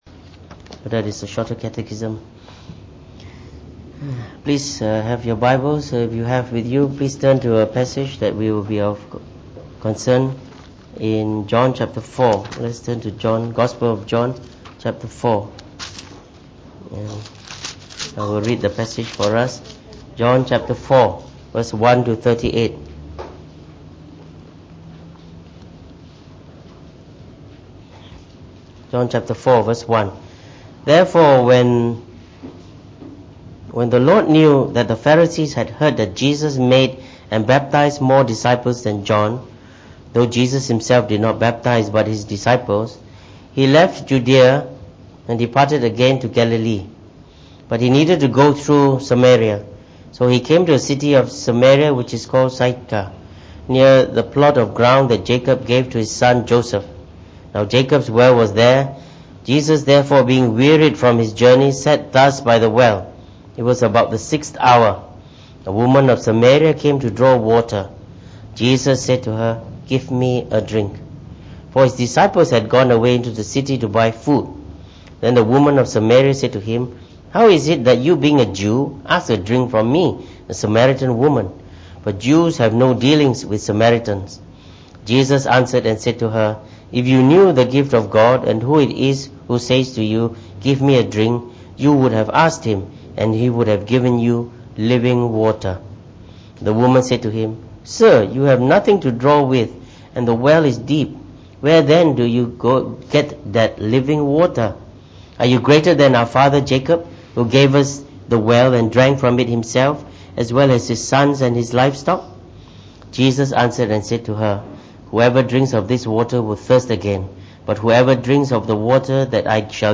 Preached on the 16th of September 2018.
delivered in the Morning Service